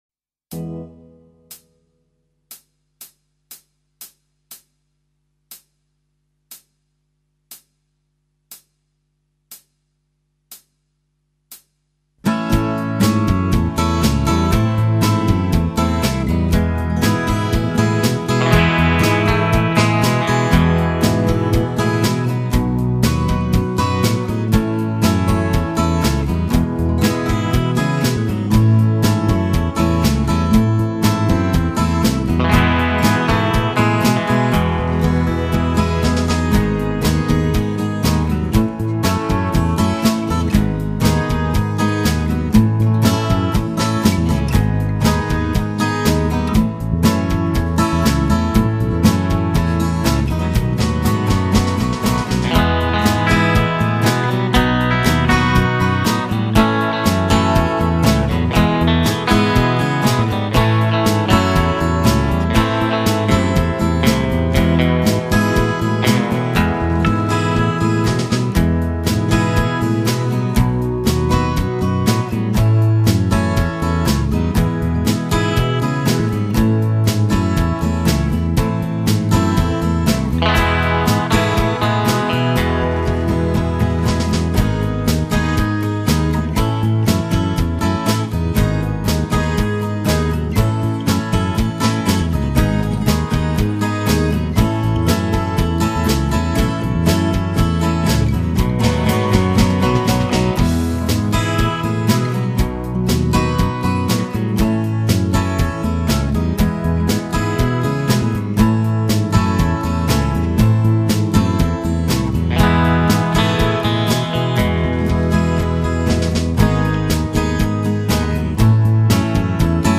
an instrumental version